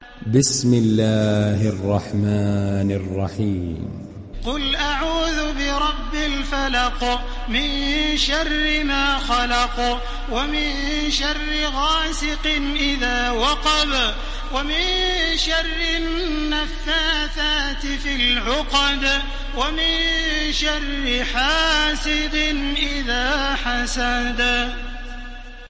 Felak Suresi İndir mp3 Taraweeh Makkah 1430 Riwayat Hafs an Asim, Kurani indirin ve mp3 tam doğrudan bağlantılar dinle
İndir Felak Suresi Taraweeh Makkah 1430